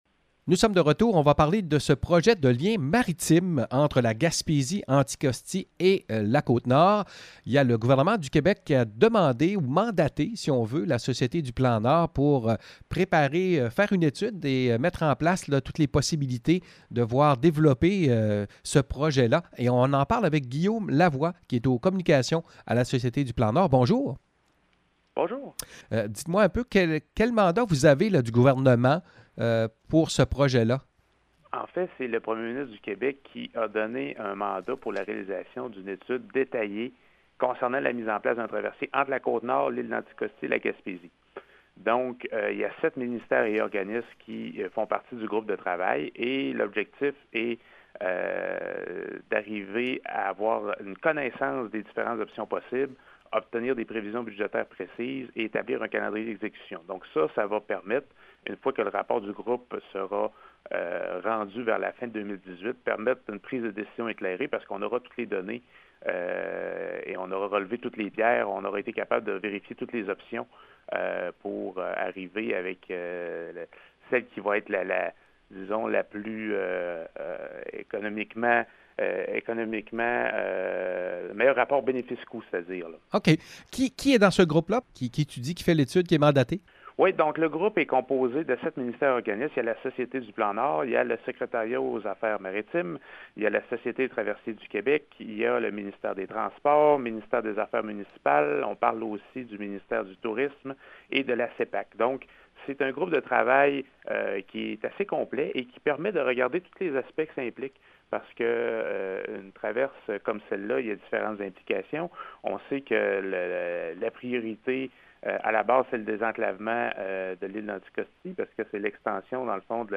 Entrevue avec le maire de Gaspé, Daniel Côté, qui commente l’arrivée de Grande-Vallée dans le dossier: